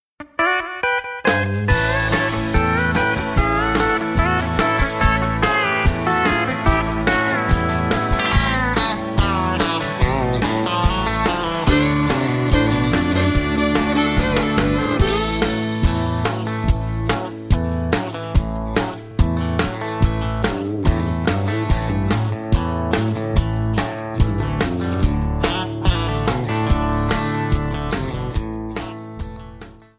D
Backing track Karaoke
Country, 2000s